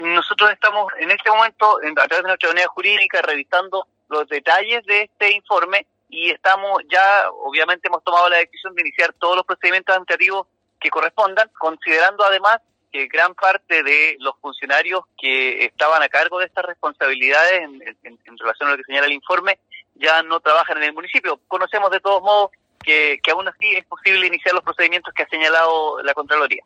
Al respecto, el alcalde César Huenuqueo, detalló las medidas que adoptaron.